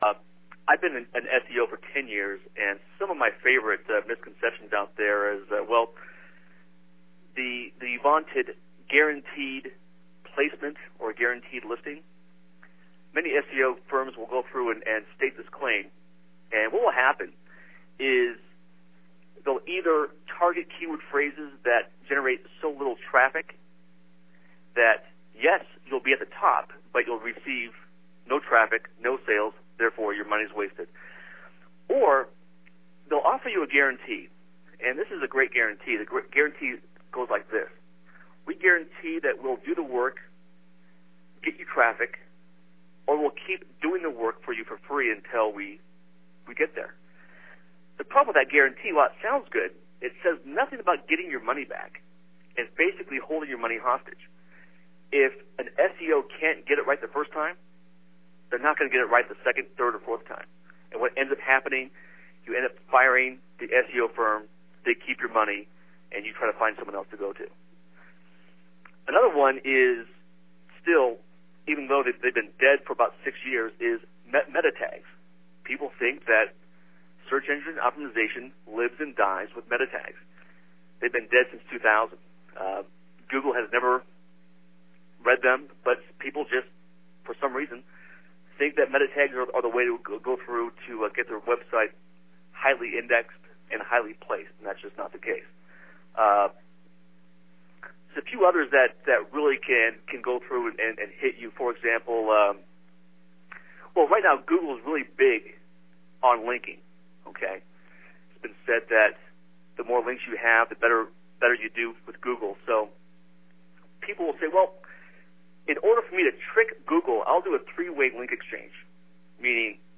This powerful 60 minute mp3 audio interview cuts through the rubbish and tells it like it is, with cutting edge information on topics such as: